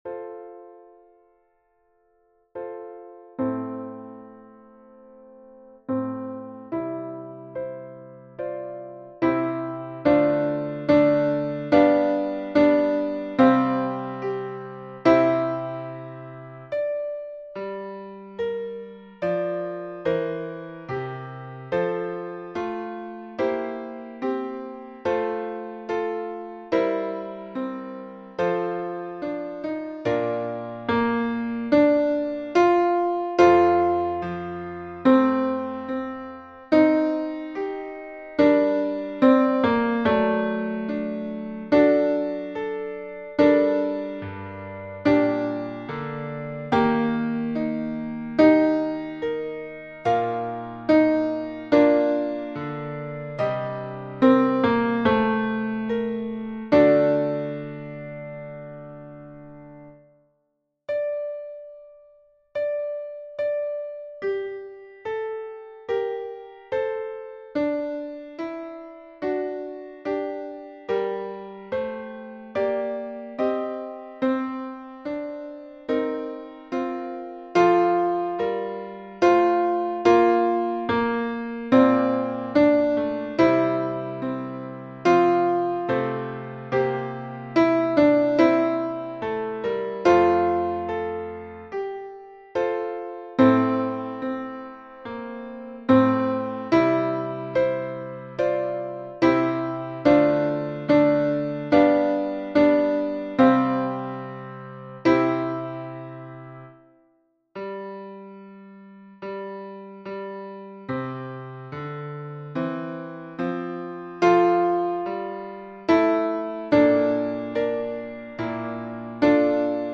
Tenor 1